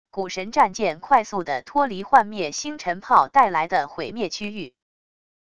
古神战舰快速的脱离幻灭星辰炮带来的毁灭区域wav音频